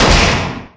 Close2.ogg